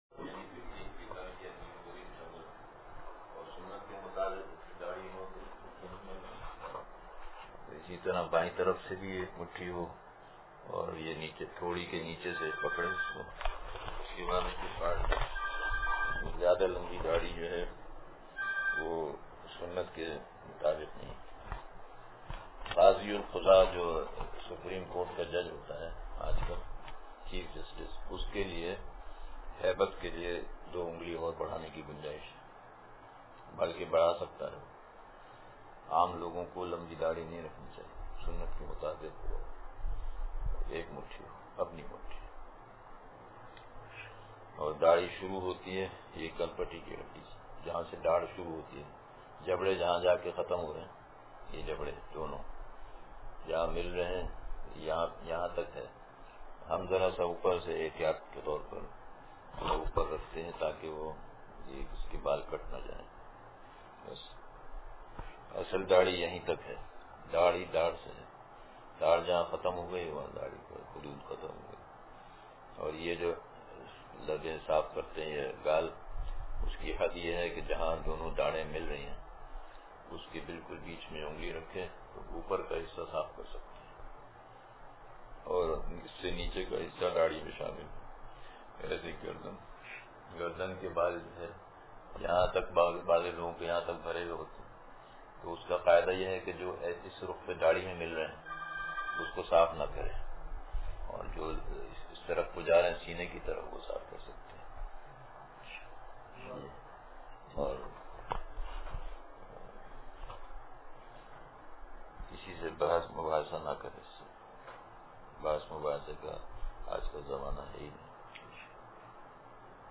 قلبِ مضطر – مجلس بروز اتوار